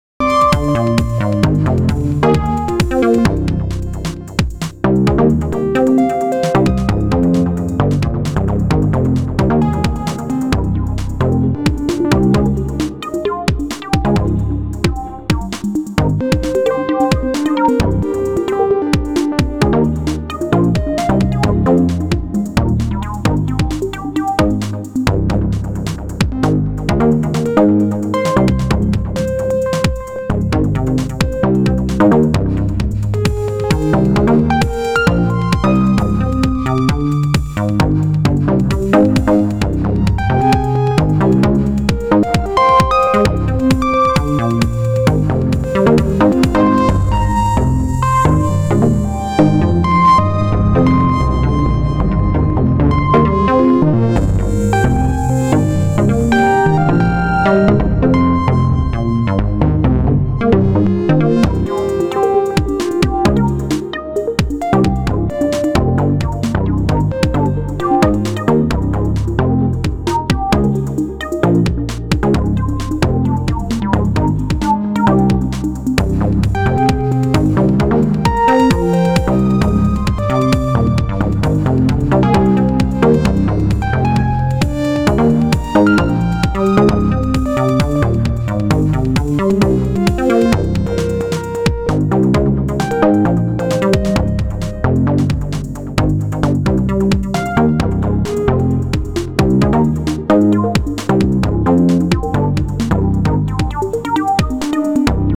rytm solo. what a great update.
All synths sounds are internal.
Bass is Sy Raw
Lead is Sy Chip
Padish sound is Sy Chip
Sub bass in the middle is Dual VCO
BD is Acoustic
Also a snare sample